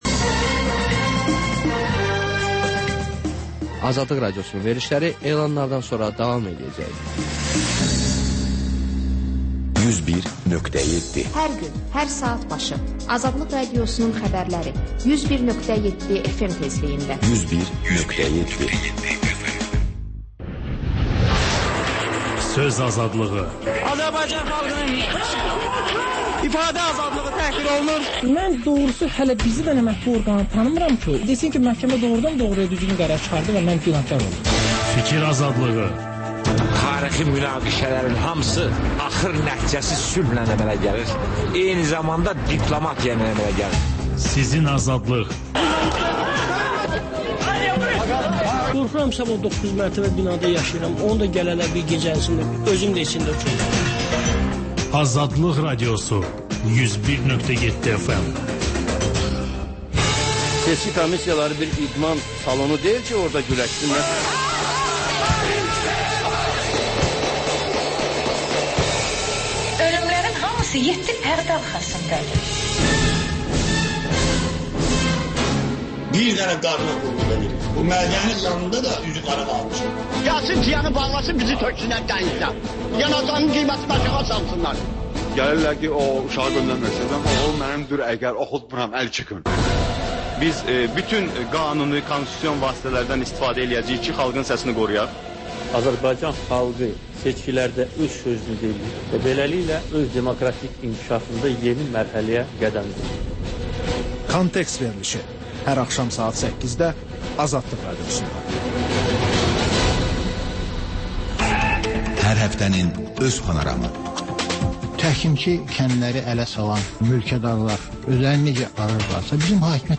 Xəbərlər, sonra CAN BAKI: Bakının ictimai və mədəni yaşamı, düşüncə və əyləncə həyatı… Həftə boyu efirə getmiş CAN BAKI radioşoularında ən maraqlı məqamlardan hazırlanmış xüsusi buraxılış (TƏKRAR)